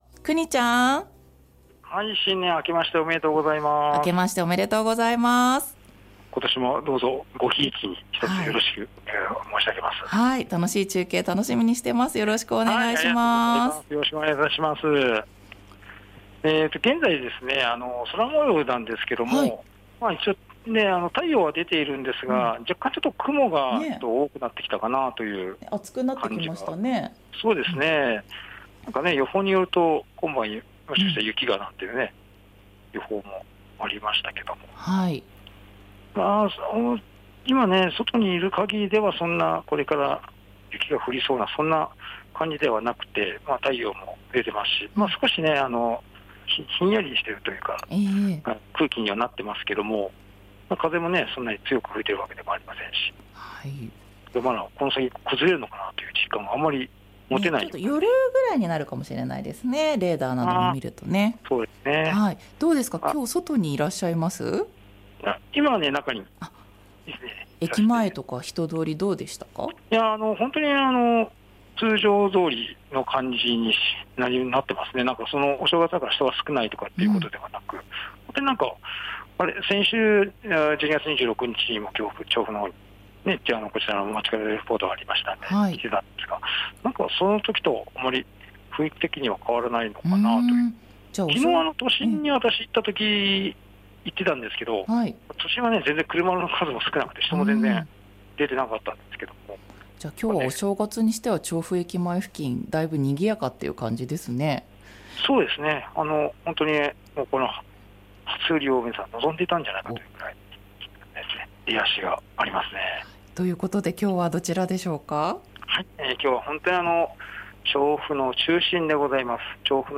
午後のカフェテラス 街角レポート